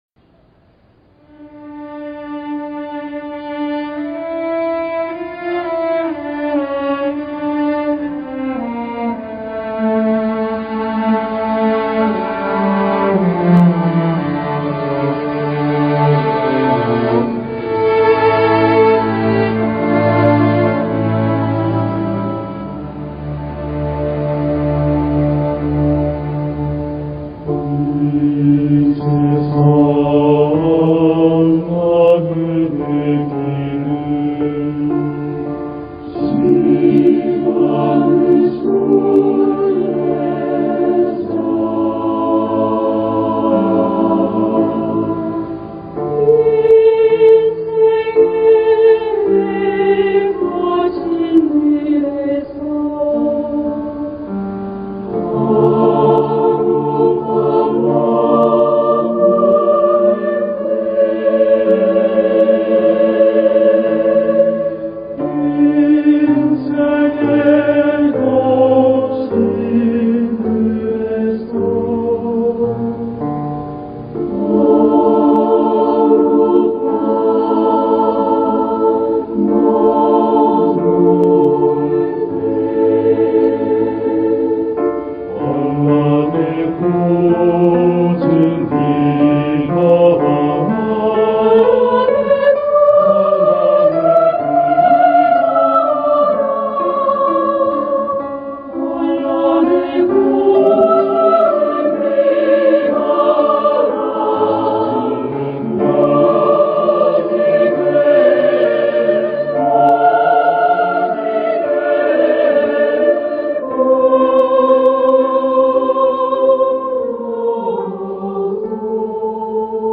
GoodNewsTV Program 성가공연 본향을 향하네